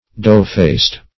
\Dough"-faced`\